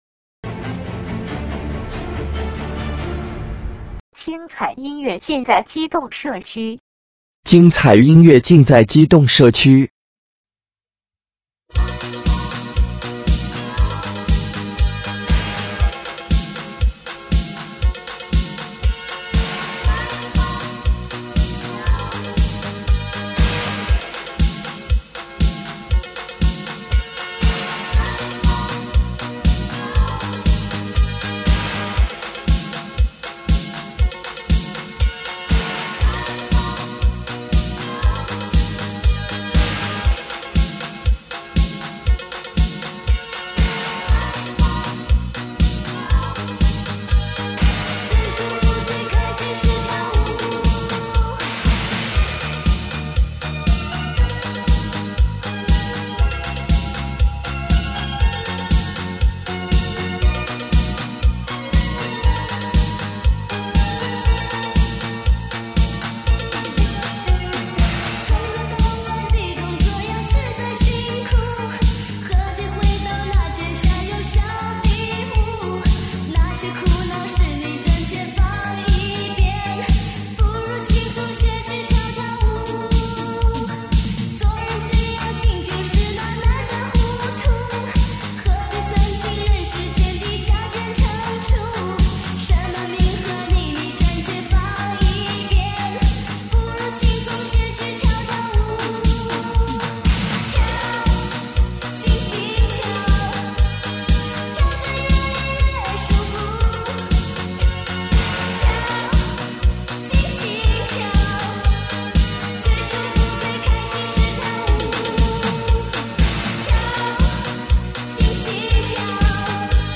老磁带舞曲专辑